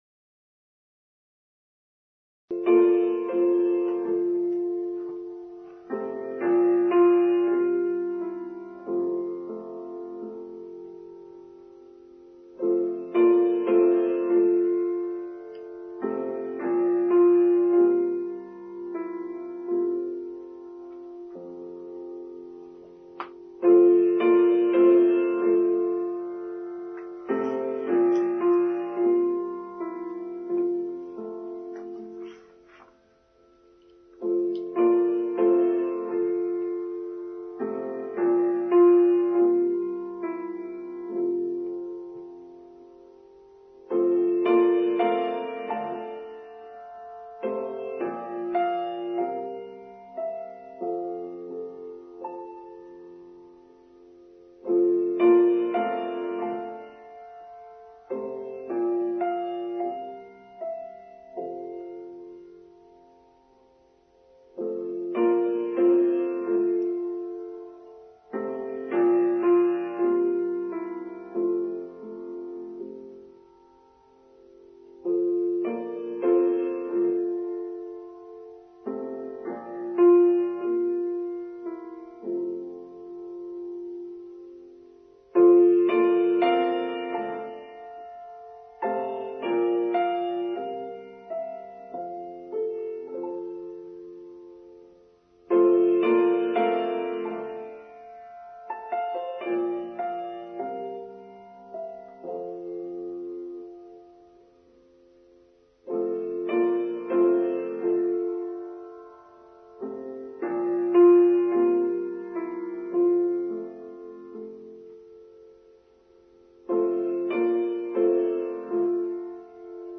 Cheerfulness: Online Service for Sunday 12th February 2023